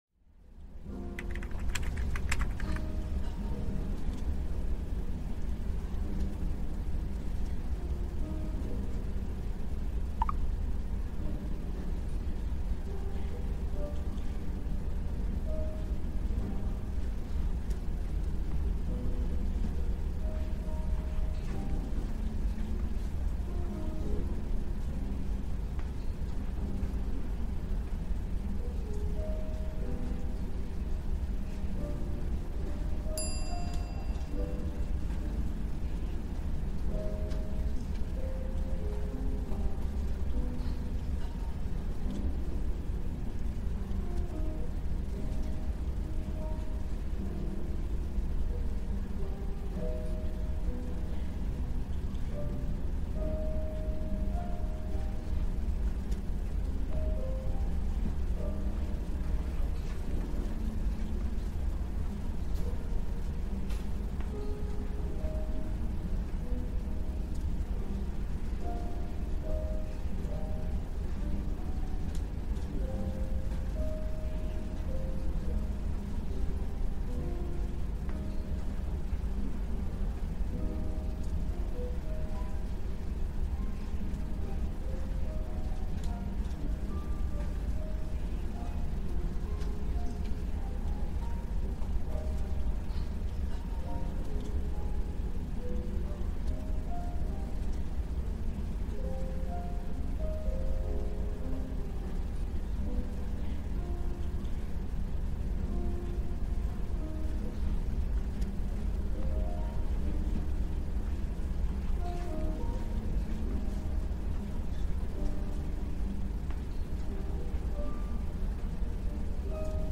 Chicago Naturelle : Pluie Rivière